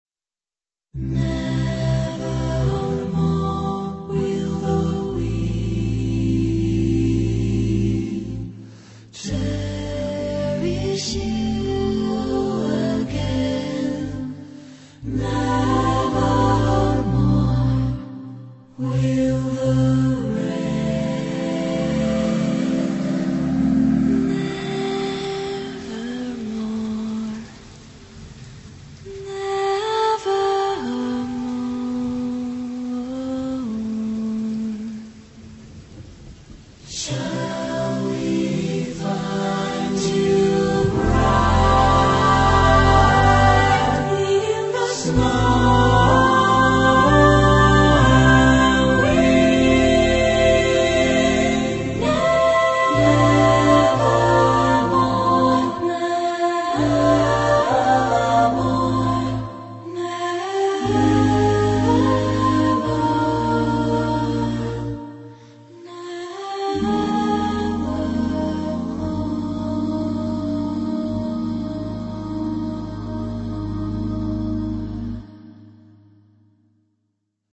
Genre-Style-Forme : Profane ; Jazz vocal ; Close Harmony
Caractère de la pièce : belles sonorités ; tendre ; doux
Type de choeur : SATB  (4 voix mixtes )
Tonalité : accords de jazz